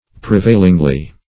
Prevailingly \Pre*vail"ing*ly\